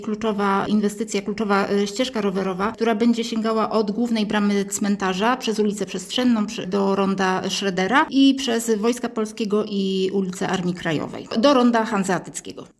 Wiceburmistrz Anita Jurewicz uważa, że jej realizacja znacznie ułatwi miłośnikom jednośladów poruszanie się po mieście.